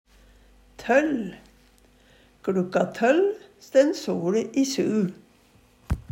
DIALEKTORD PÅ NORMERT NORSK tøll tolv, talord, grunntalet 12 Eksempel på bruk KLukka tøll stænn soLe i su.